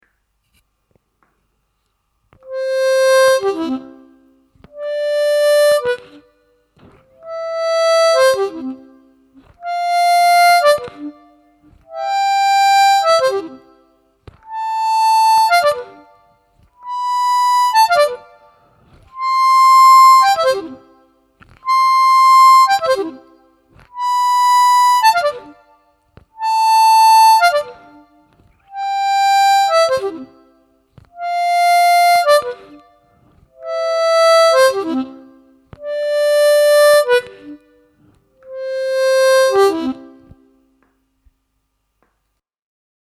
Слайд (глиссандо) и дроп-офф на губной гармошке
Сыграть гамму «до мажор» +4-4+5-5+6-6-7+7 и обратно +7-7-6+6-5+5-4+4, после каждой ноты играя дроп-офф (вниз).
gamma-dropoff-vniz.mp3